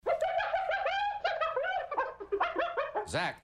This nocturnal dog-like animal of Africa is heard